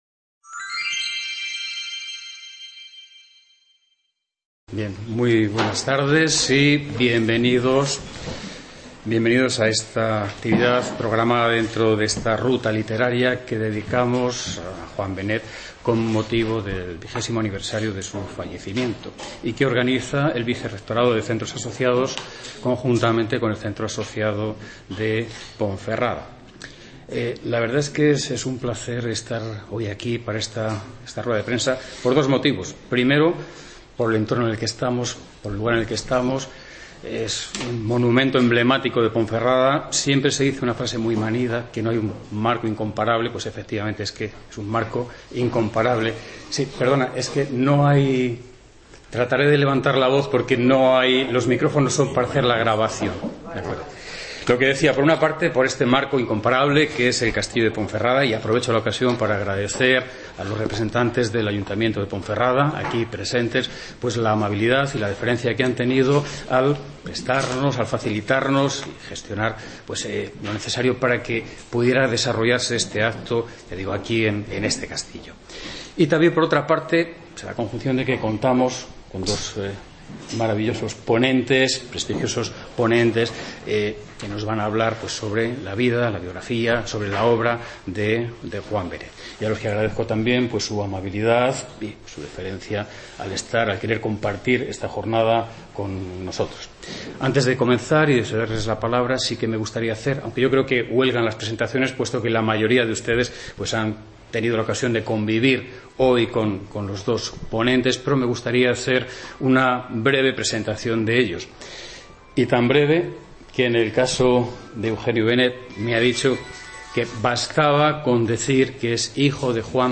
MESA REDONDA en el Castillo de Ponferrada